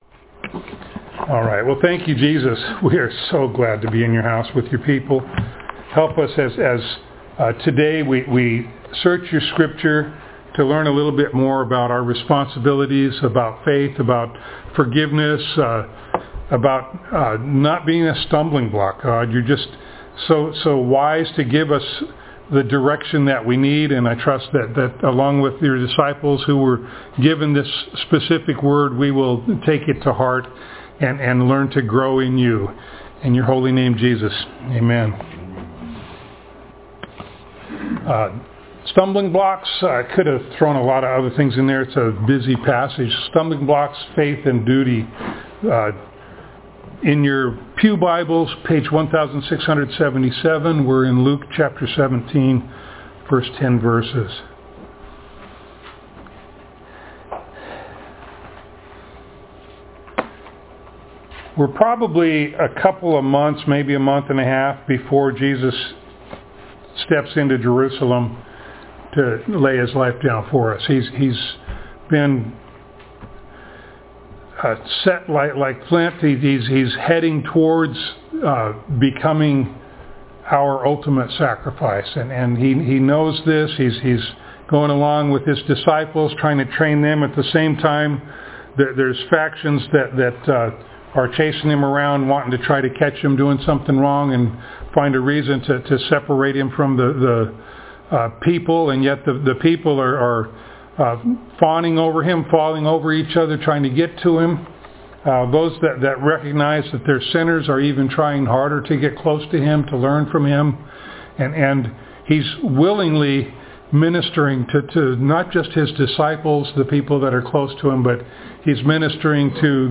Luke Passage: Luke 17:1-10 Service Type: Sunday Morning Download Files Notes « Lovers of Money Cleansed